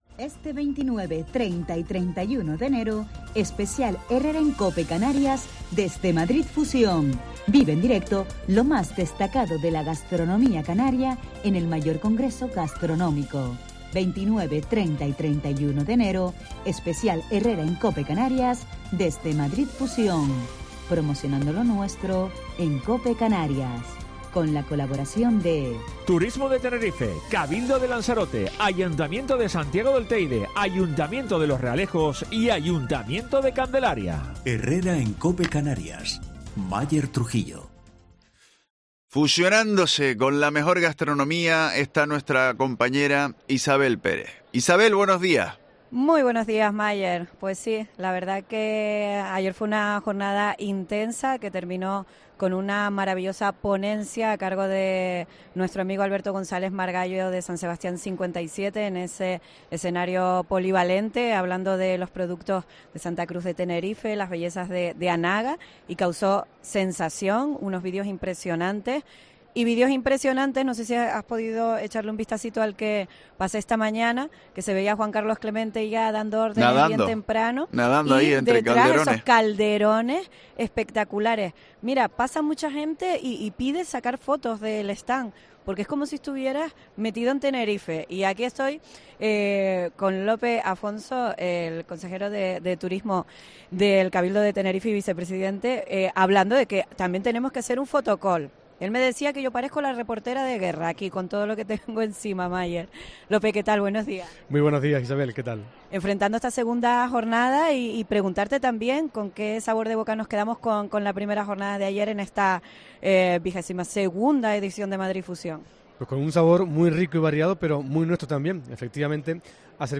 Entrevista
Madrid Fusión